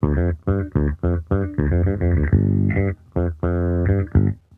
Index of /musicradar/sampled-funk-soul-samples/105bpm/Bass
SSF_JBassProc2_105G.wav